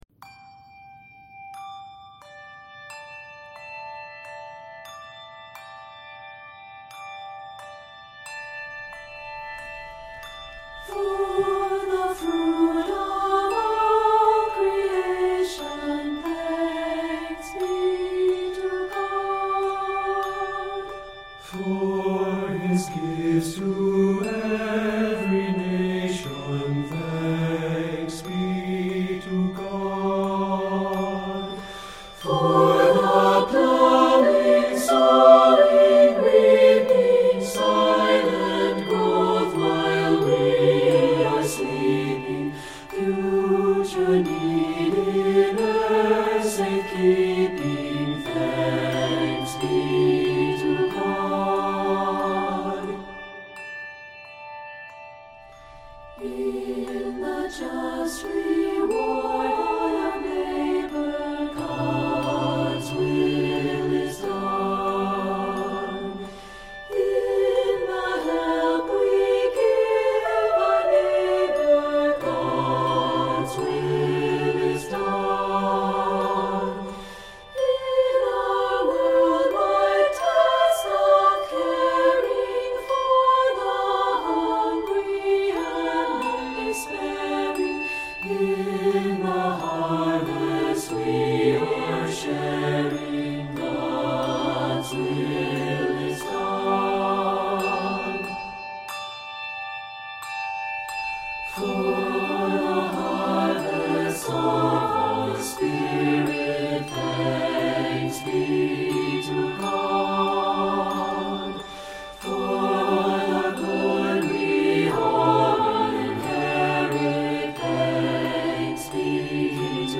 traditional Welsh melody